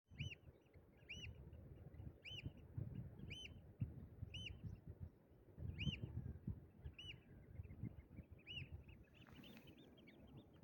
Austernfischer
Die Austernfischer auf Borkum fielen mir nicht nur durch ihr Aussehen auf, sondern auch durch ihre Rufe. Die Austernfischer haben sich im Mai als ich dort war von ihrer ruffreudigsten Seite gezeigt.